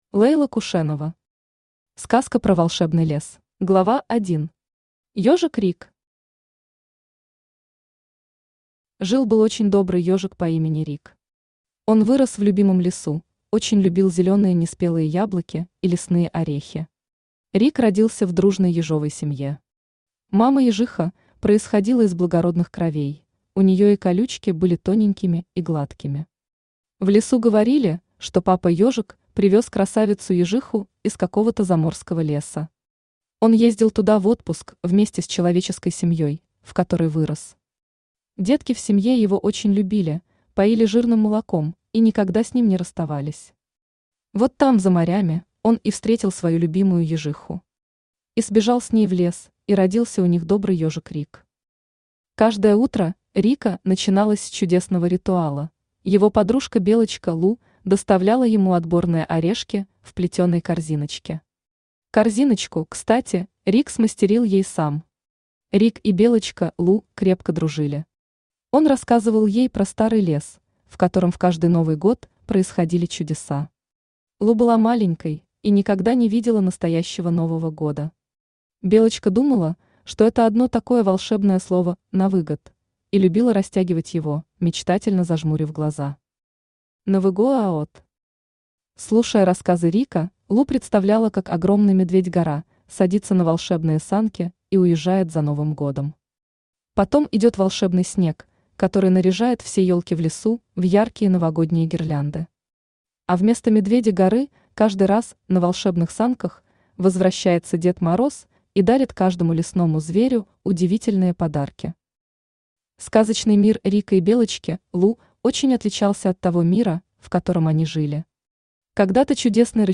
Аудиокнига Сказка про волшебный лес | Библиотека аудиокниг
Читает аудиокнигу Авточтец ЛитРес.